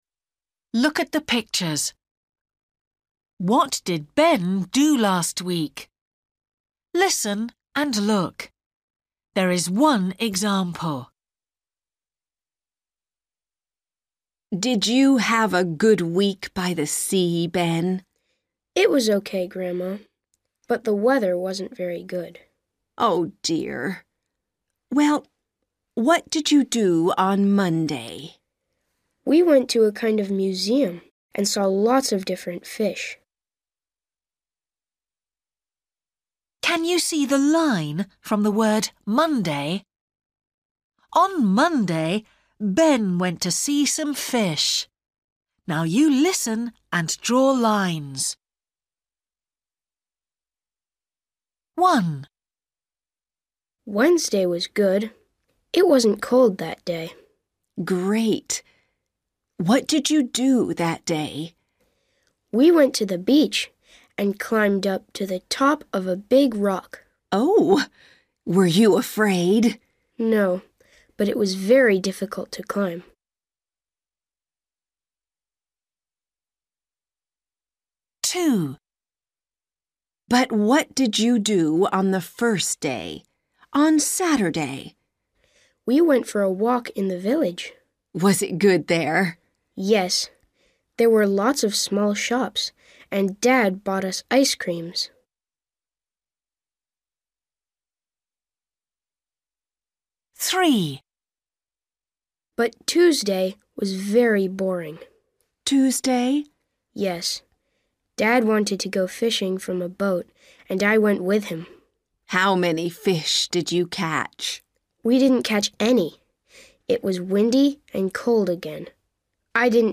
Practice English Listening Test for A1 – Listening for Names and Everyday Activities Test #8